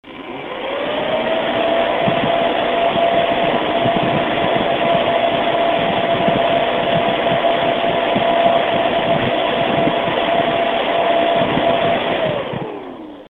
FrostyTech Acoustic Sampling Chamber